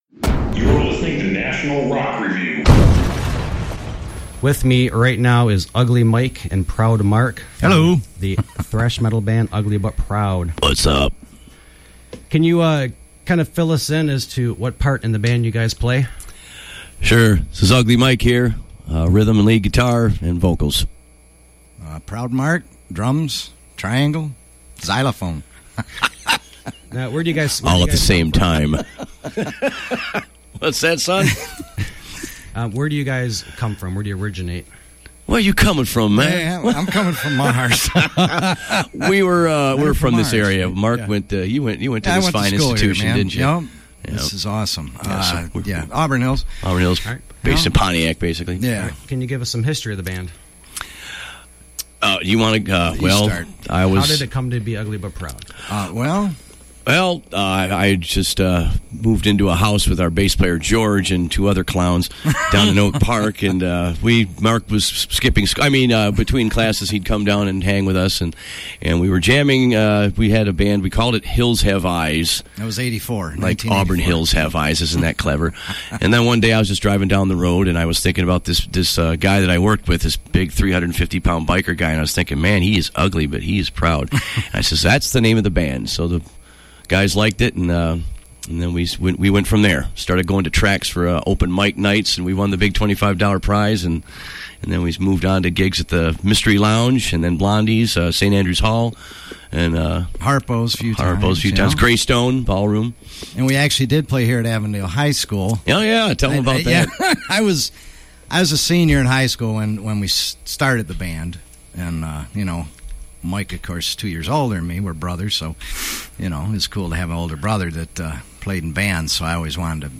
Avondale High School, located in Auburn Hills, Michigan, had a 40th Anniversary Alumni Radio-a-Thon to raise money for their station, 89.5 WAHS.